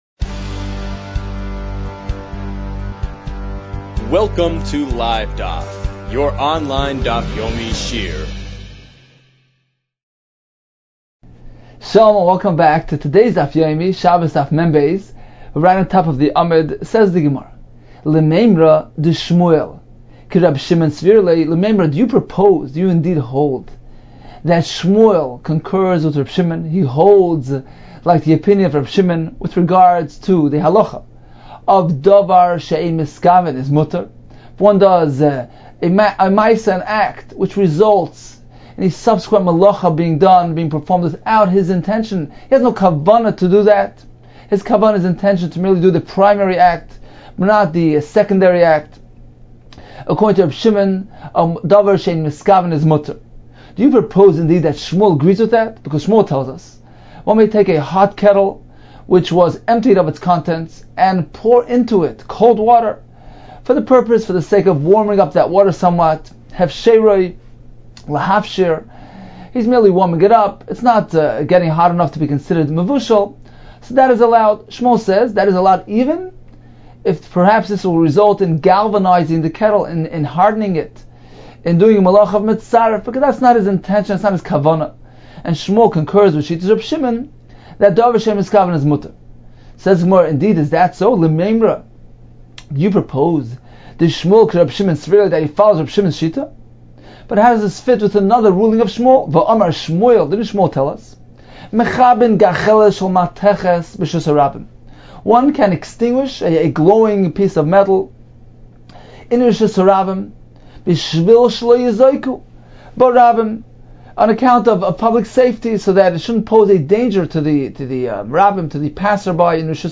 Agudath Israel of Toronto (South) presents this daily Daf Yomi Shiur on Shabbos Daf 42 with on-screen slide displays at every section of the Gemara as well as during the end-shiur Daf review